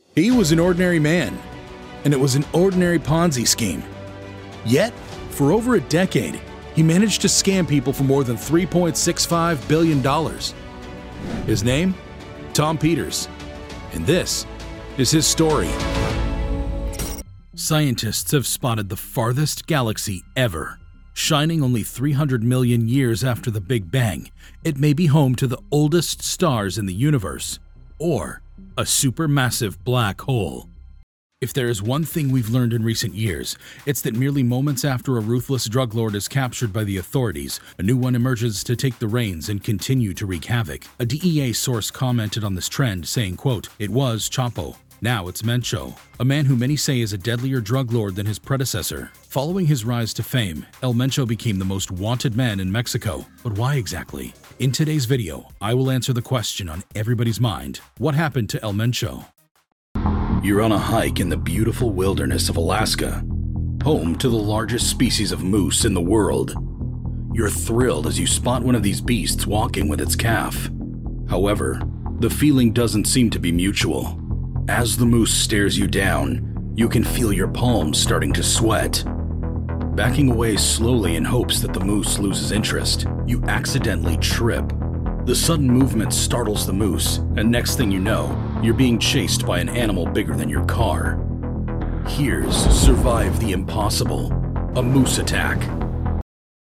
Deep, rich, and warm tones for commercials, narration, and more.
Narration
Clear, confident, and compelling storytelling.
• Acoustically Treated Space
demo-narration.mp3